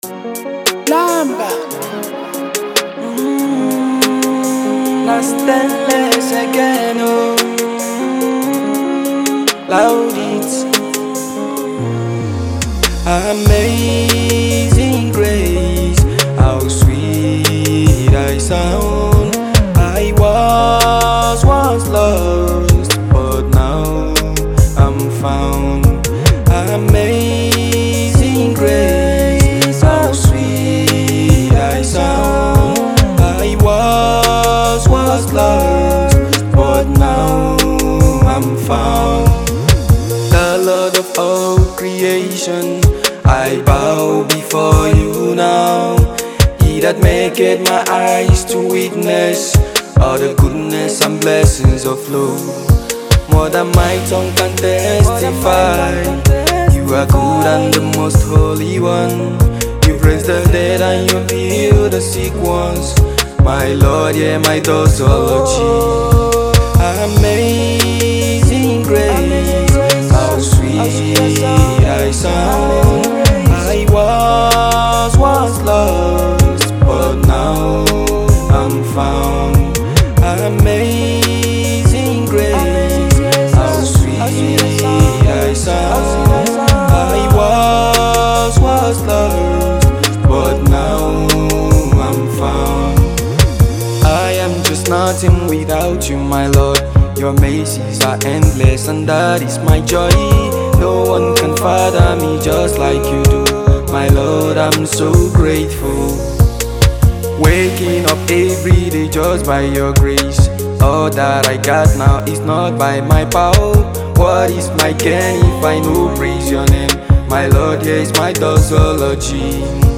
Afrobeat